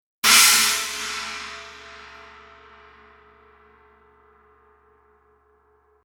破裂音のような抜けの良いアタックと独特のサスティーンでエフェクト、アクセントに最適。スティックワークによってさまざま表情を持ち独特の形状をしたカップはオーバートーンを防ぎます。